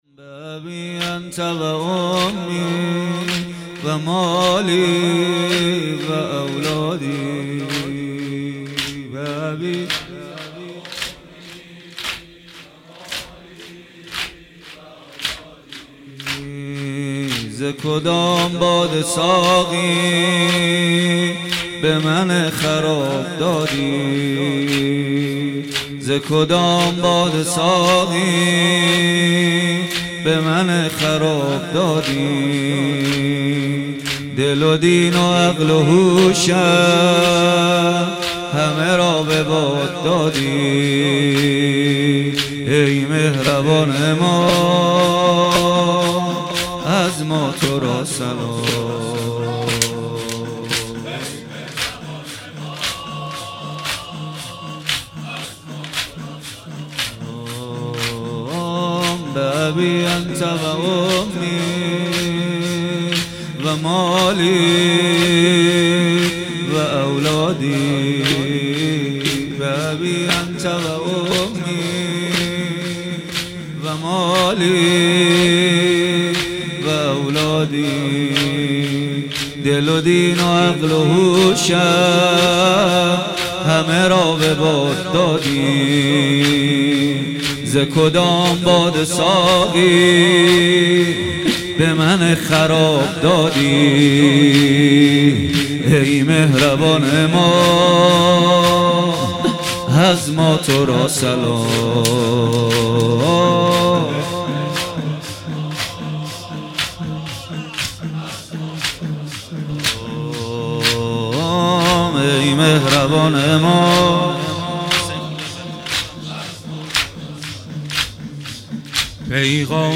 هیأت ریحانه النبی - زمینه - به ابی انت و امی